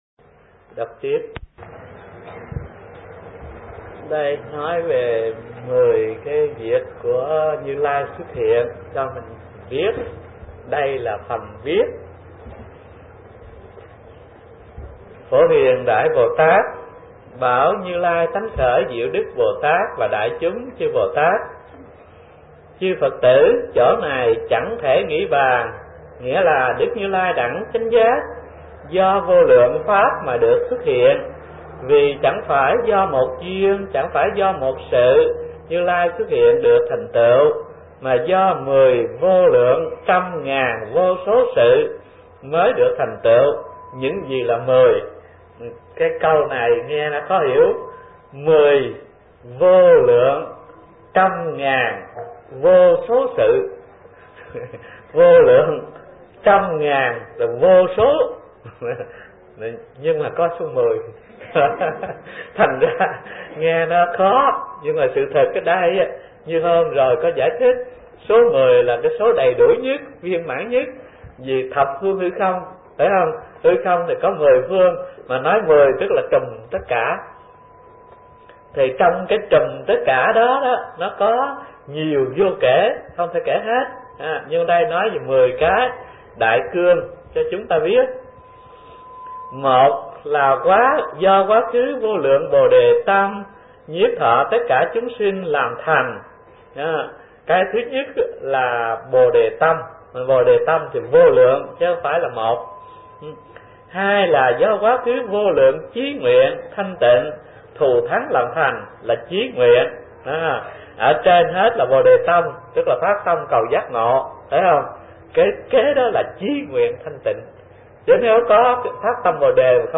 Kinh Giảng Kinh Hoa Nghiêm (Thiền Viện Linh Chiếu) - Thích Thanh Từ